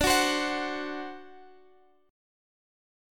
D7 Chord (page 3)
Listen to D7 strummed